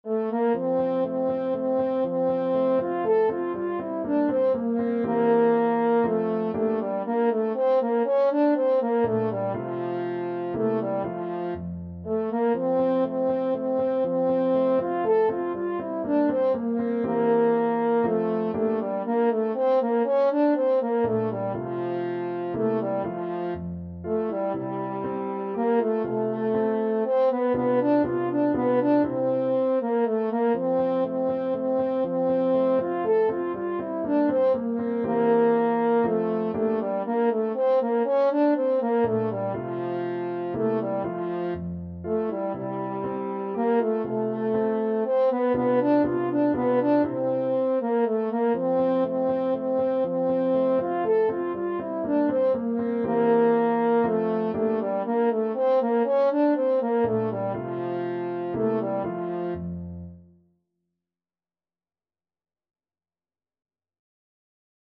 French Horn
F major (Sounding Pitch) C major (French Horn in F) (View more F major Music for French Horn )
3/4 (View more 3/4 Music)
Classical (View more Classical French Horn Music)